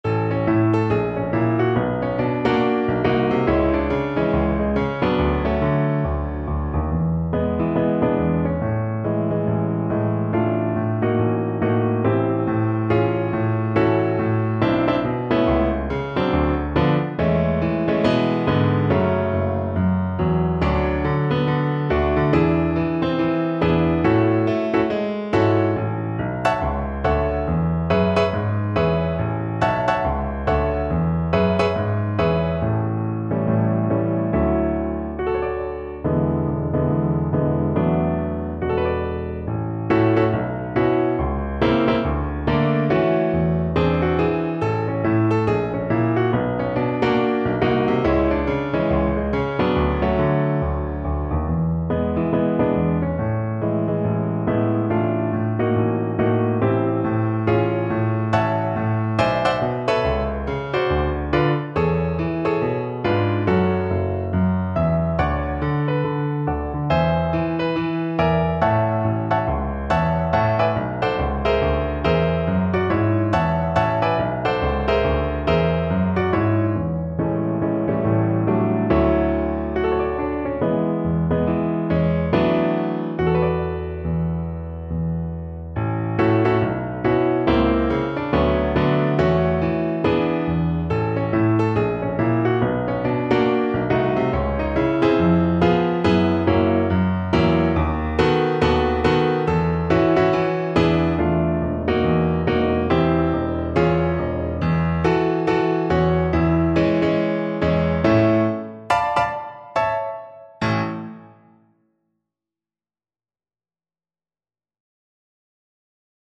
4/4 (View more 4/4 Music)
Fast swing =c.140